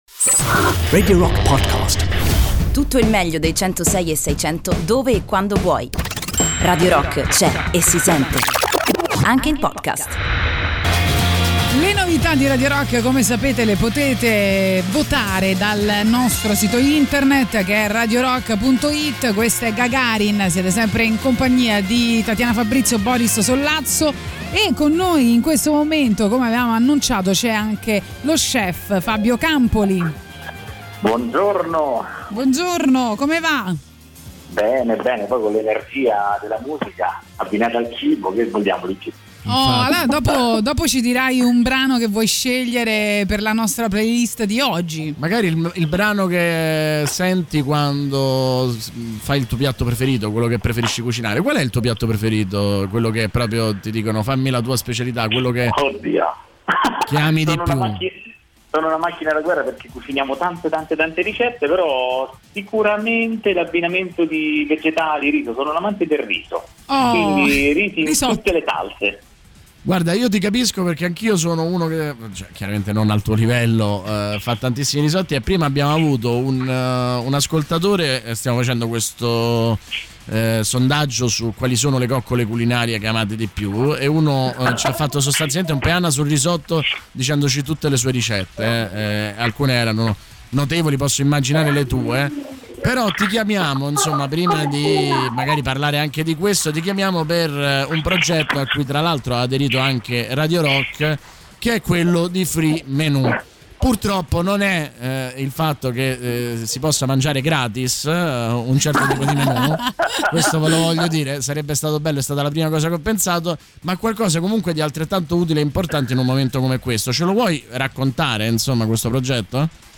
Intervista
in collegamento telefonico